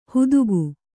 ♪ hudugu